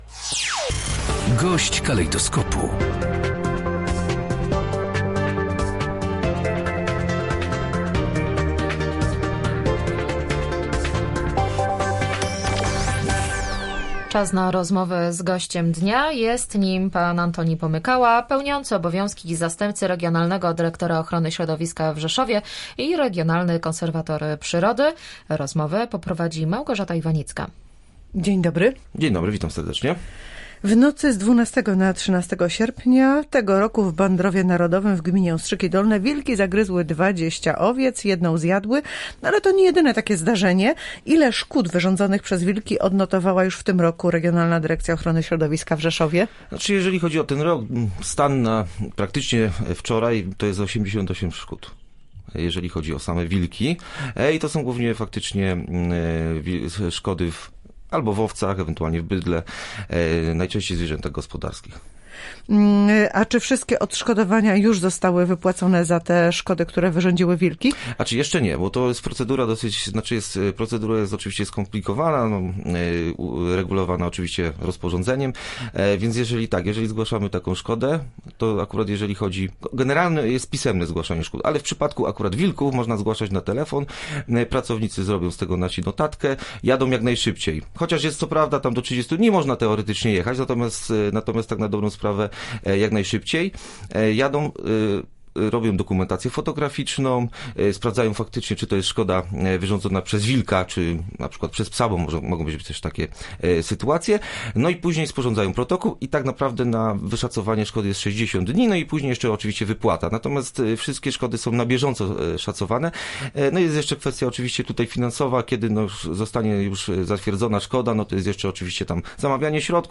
-Zwierzęta były zamknięte, na ogrodzonym terenie – wyjaśniał na naszej antenie zastępca regionalnego dyrektora ochrony środowiska i regionalny konserwator przyrody Antoni Pomykała.